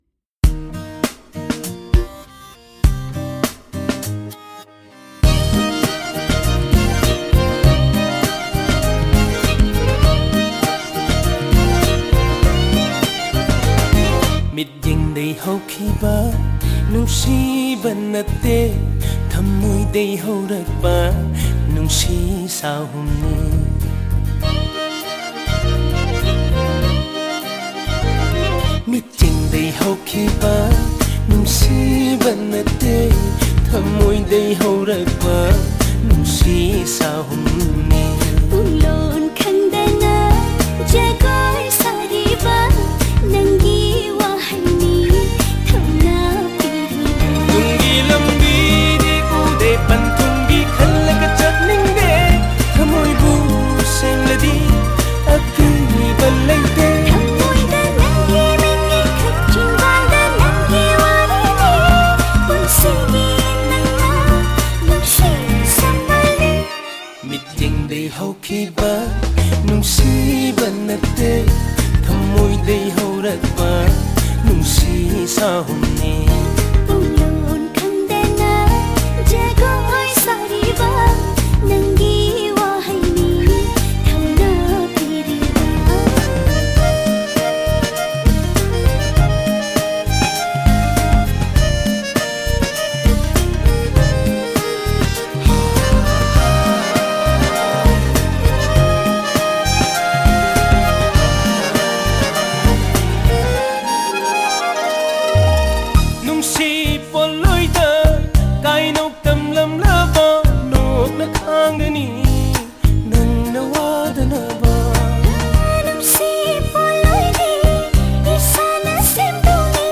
Manipuri feature film